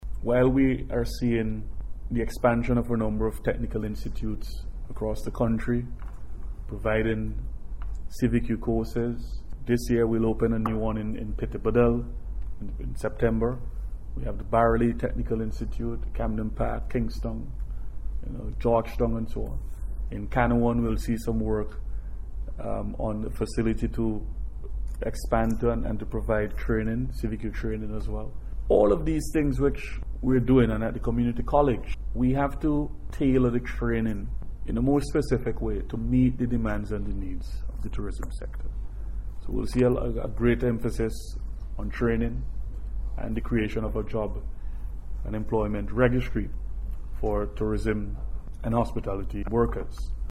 So says the Minister of Tourism Carlos James while speaking at a News Conference on Monday at the NIS Conference Room.